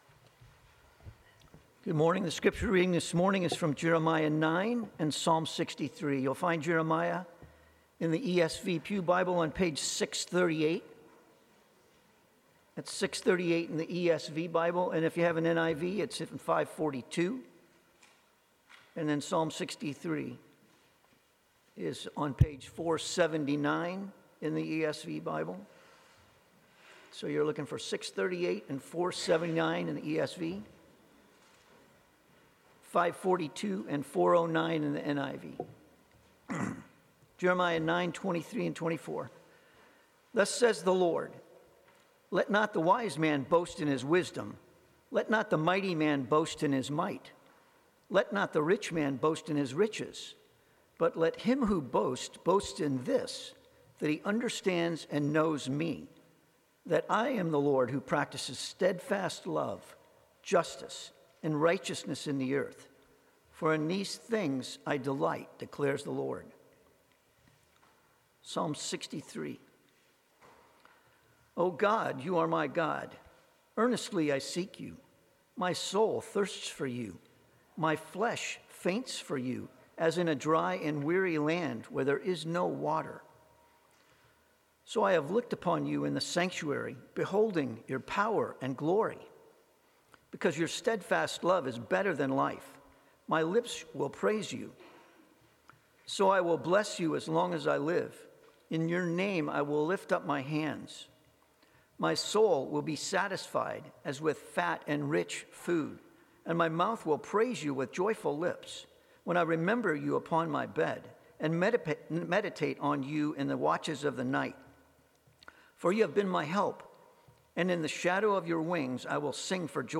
Passage: Jeremiah 9:23-24 Sermon